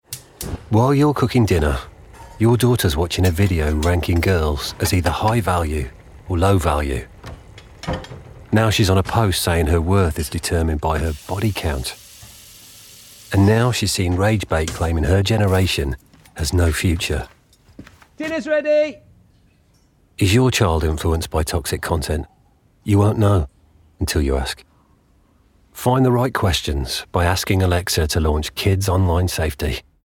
The ad puts the listener in a familiar setting, right there in the kitchen with a dad making dinner, while his daughter is somewhere else in the house…quietly absorbing toxic and harmful information online. No visuals, just voices, carefully crafted sound effects and our imagination uncomfortably picturing our own children.